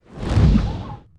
fire1.wav